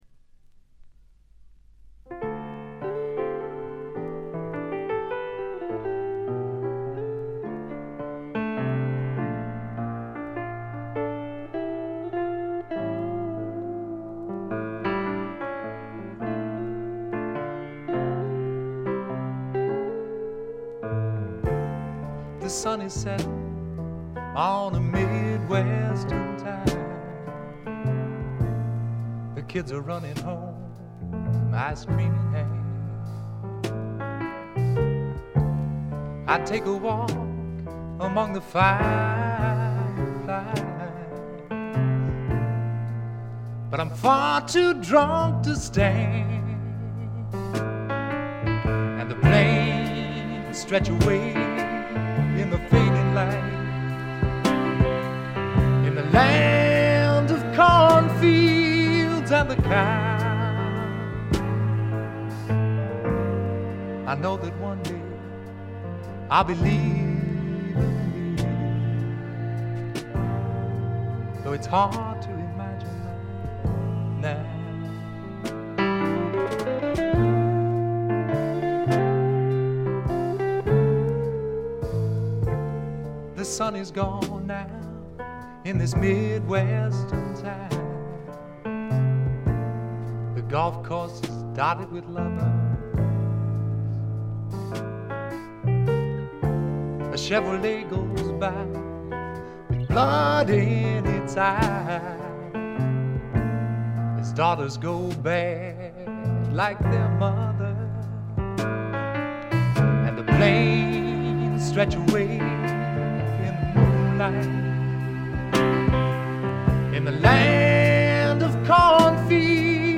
ごくわずかなノイズ感のみ。
シンプルなバックに支えられて、おだやかなヴォーカルと佳曲が並ぶ理想的なアルバム。
試聴曲は現品からの取り込み音源です。